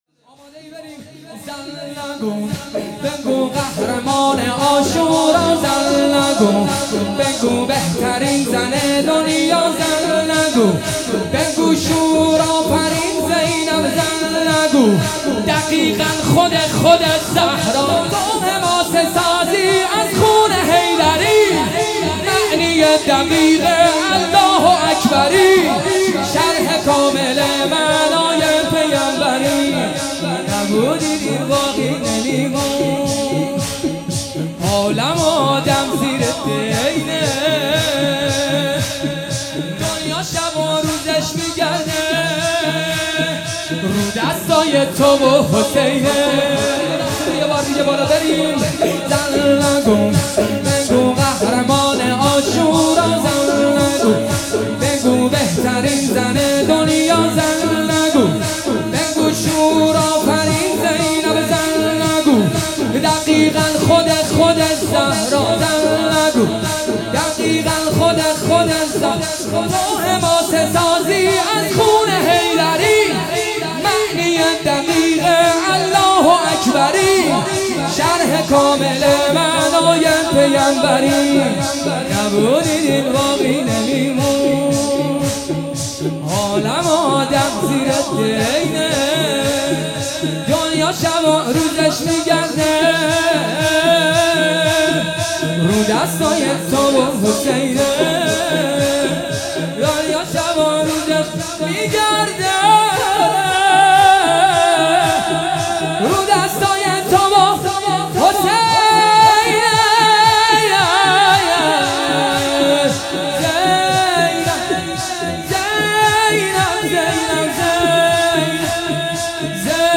محرم 1399 | هیئت عشاق الرضا (ع) تهران